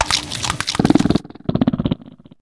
Cri de Lestombaile dans Pokémon HOME.